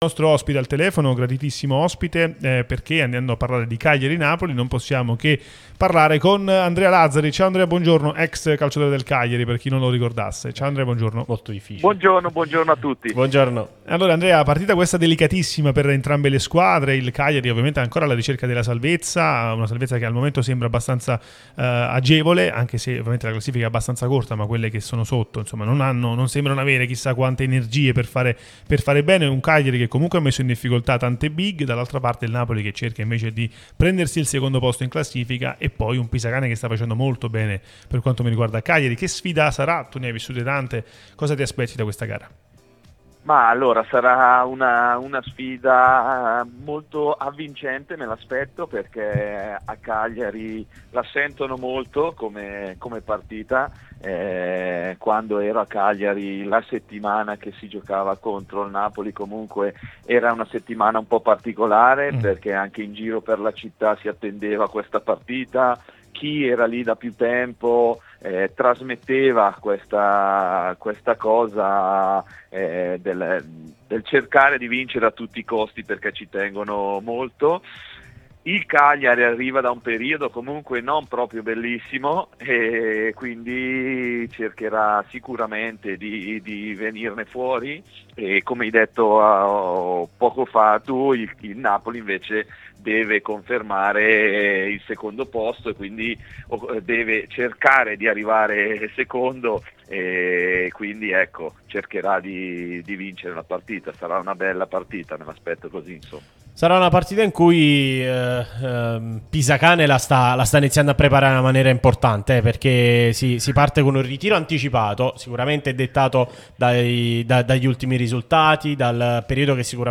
ex giocatore del Cagliari, è stato nostro ospite su Radio Tutto Napoli